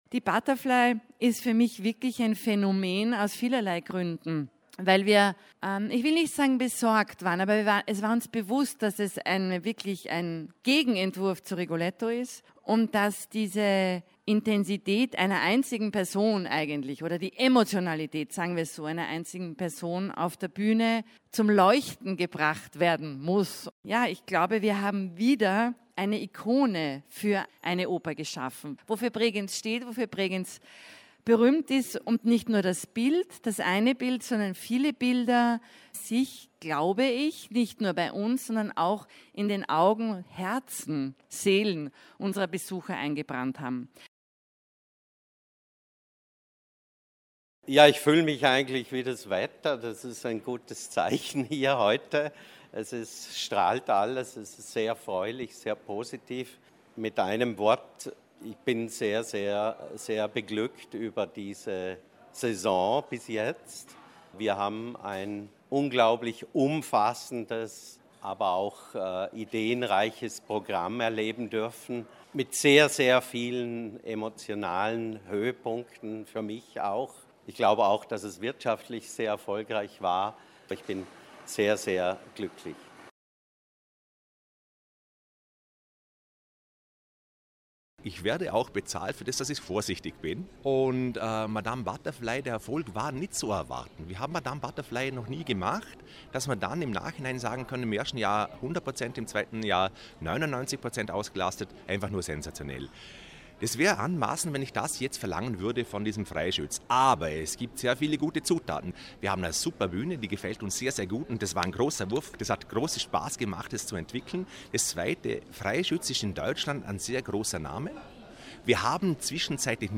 O-Ton Pressekonferenz Vorläufige Bilanz - Feature